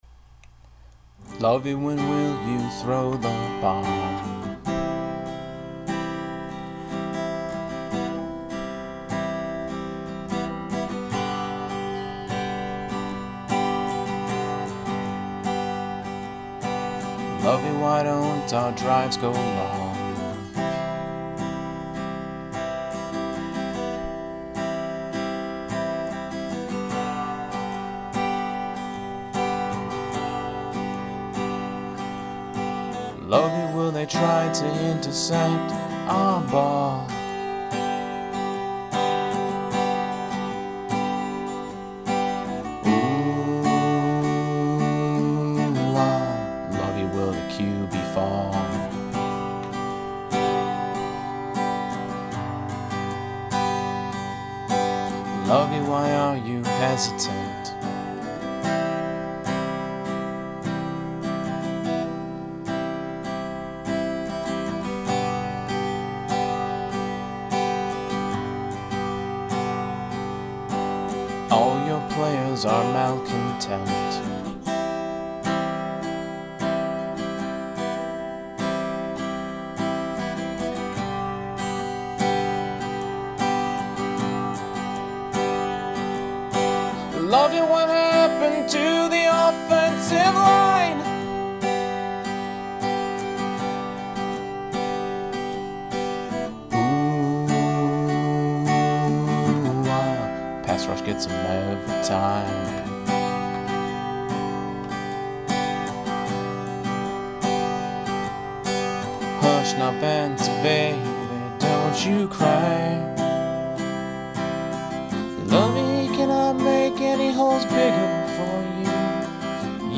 Grabbed my acoustic and recorded it.